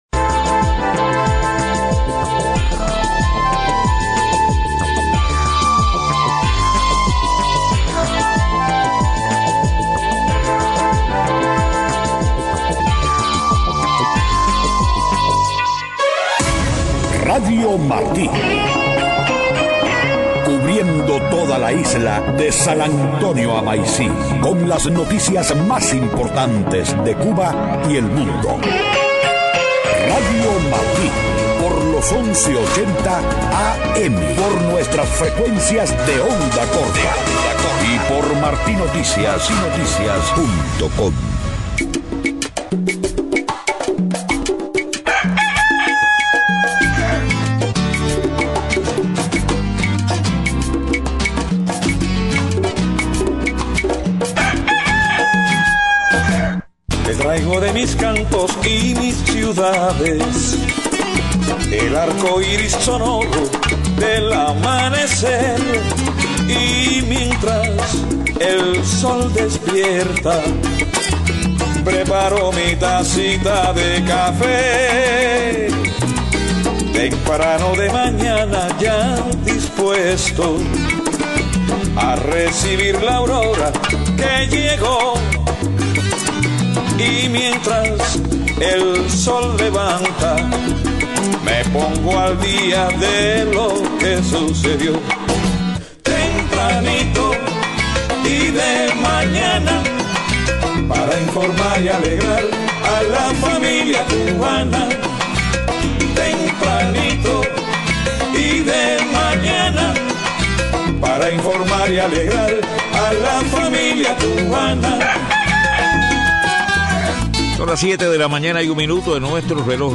7:00 a.m Noticias: No satisface a creadores independientes cubanos el resultado de Congreso de UNEAC.